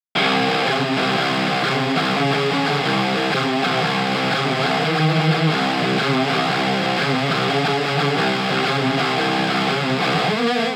サウンドデモ
エレキギター（SA-3適用後）
SA-3_ElectricGuitar_Engaged.wav